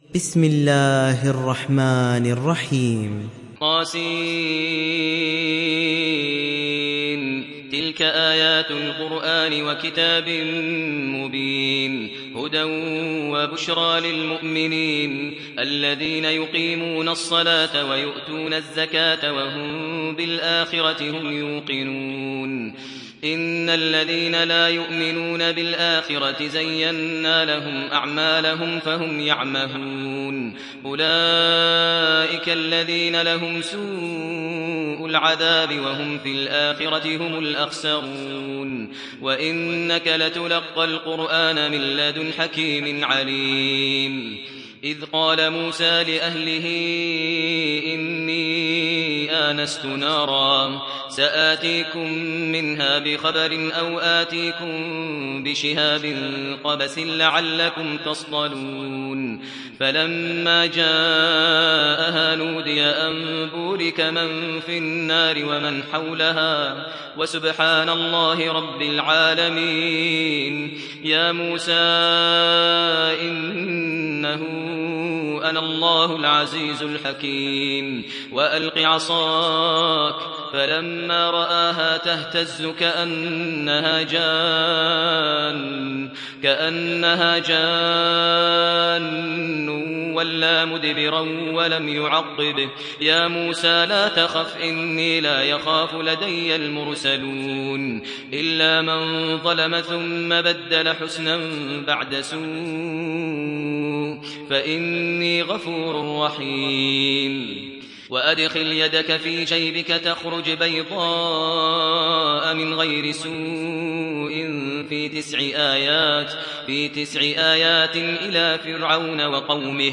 دانلود سوره النمل mp3 ماهر المعيقلي روایت حفص از عاصم, قرآن را دانلود کنید و گوش کن mp3 ، لینک مستقیم کامل